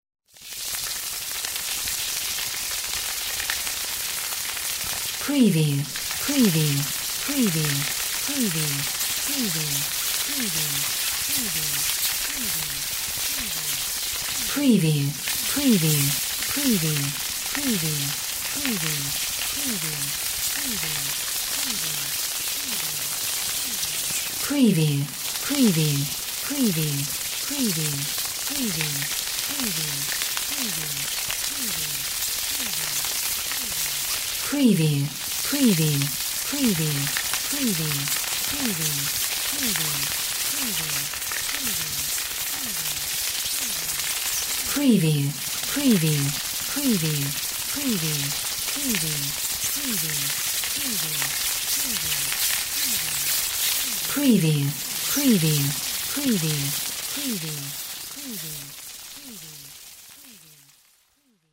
Bats swarming
Stereo sound effect - Wav.16 bit/44.1 KHz and Mp3 128 Kbps
PREVIEW_ANM_BATS_SWARM_TBSD01A.mp3